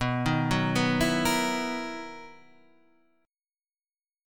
BM11 chord {7 6 4 4 5 6} chord